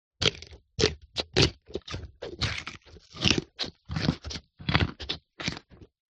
Звуки моркови
Лошадь грызет морковь